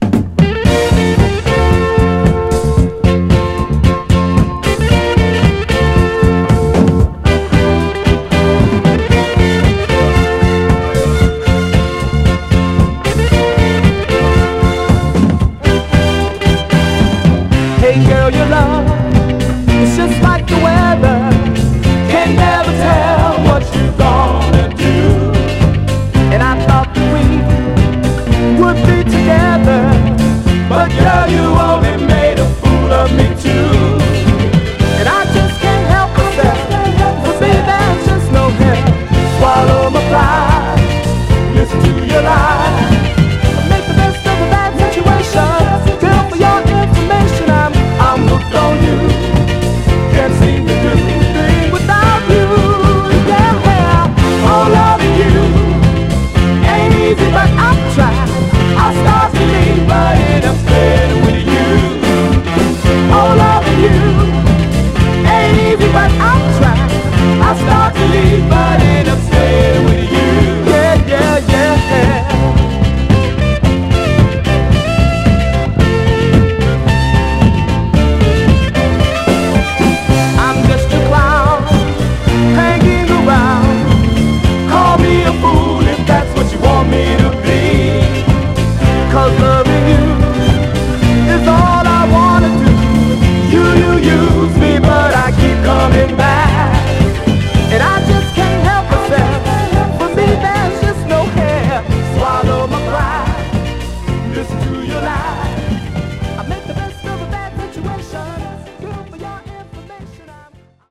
こちらの軽快モダン・ソウル・ダンサー
※試聴音源は実際にお送りする商品から録音したものです※